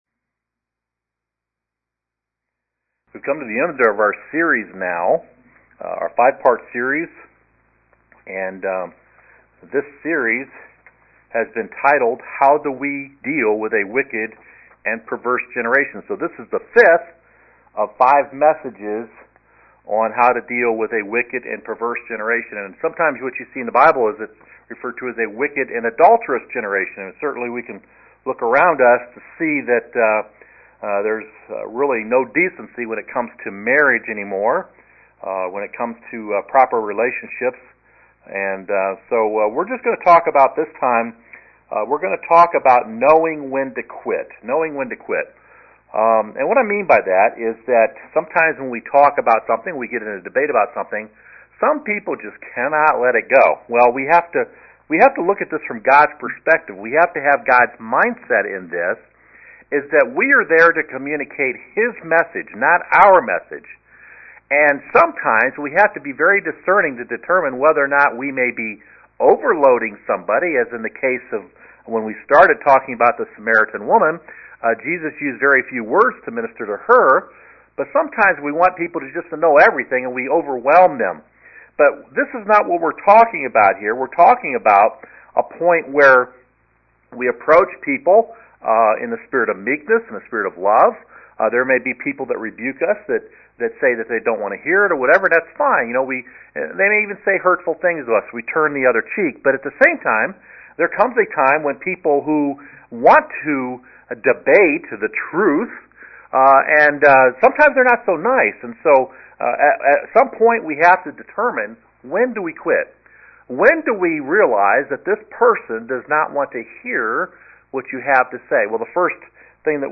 Preaching: How do we deal with a wicked and perverse generation? Part 5